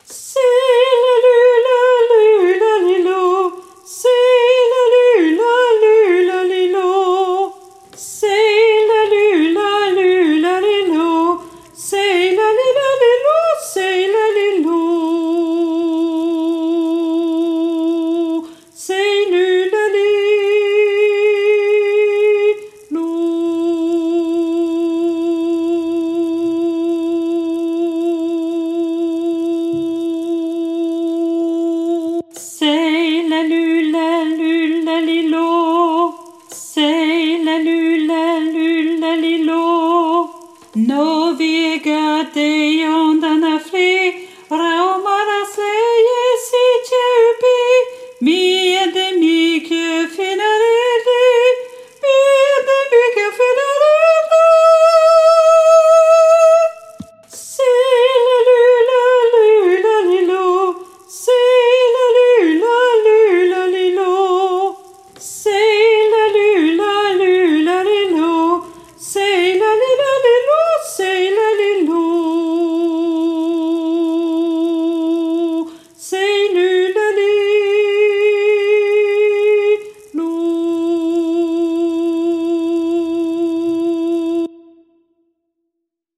Soprano + Mezzo